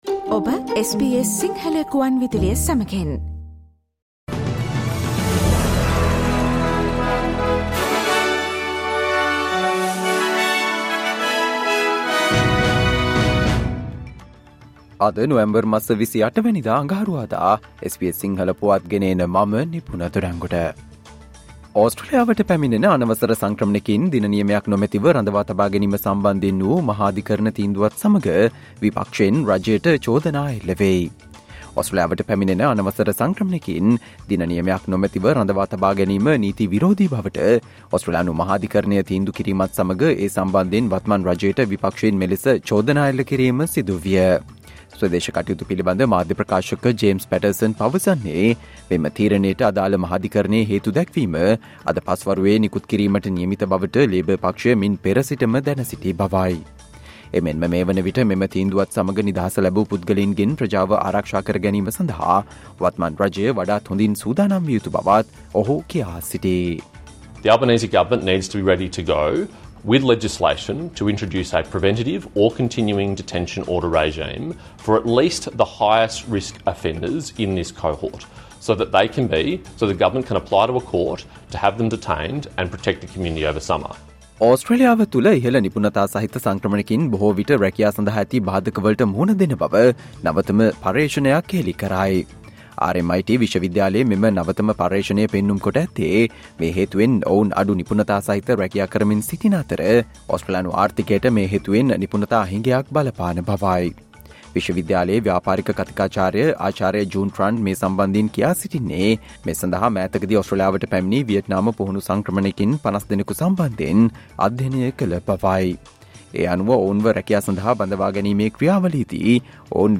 Australia news in Sinhala, foreign and sports news in brief - listen, Tuesday 28 November 2023 SBS Sinhala Radio News Flash